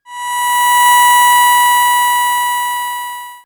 BadTransmission8.wav